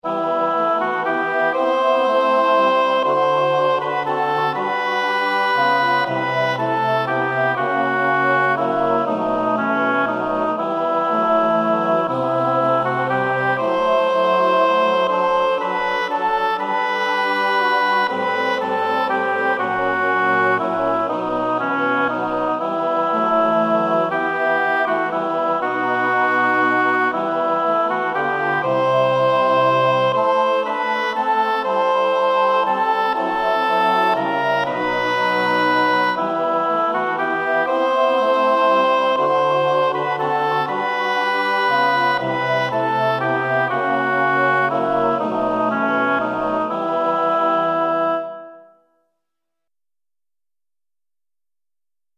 Ande och Liv sopran
ande av liv_sop.mp3